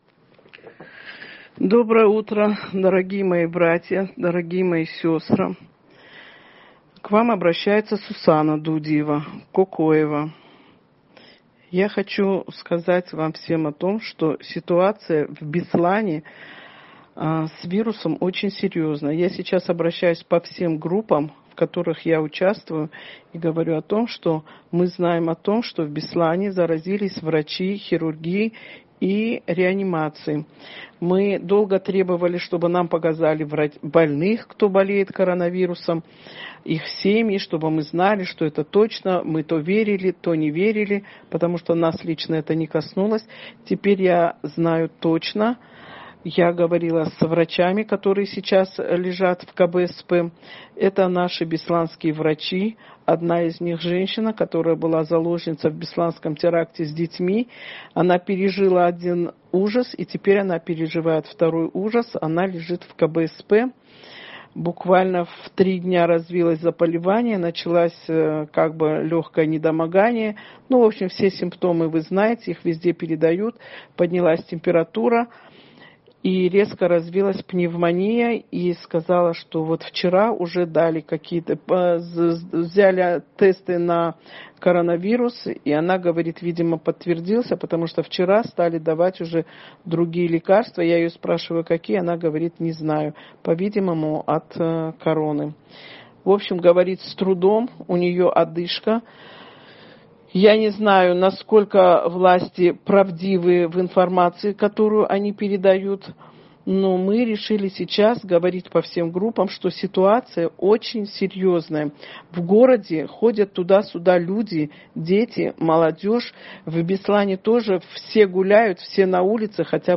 Обращение по поводу вспышки коронавирусной инфекции в Беслане